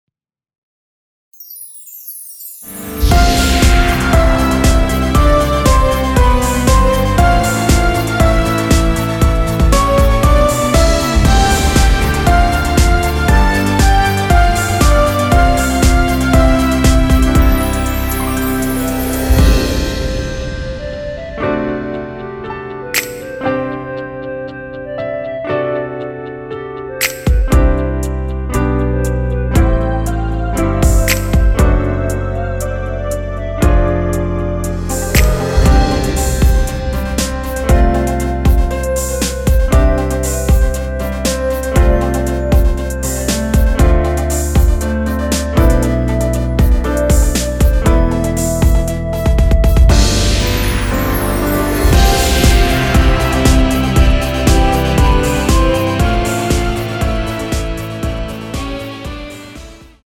원키에서(-3)내린 멜로디 포함된 MR입니다.(미리듣기 확인)
노래방에서 노래를 부르실때 노래 부분에 가이드 멜로디가 따라 나와서
앞부분30초, 뒷부분30초씩 편집해서 올려 드리고 있습니다.
중간에 음이 끈어지고 다시 나오는 이유는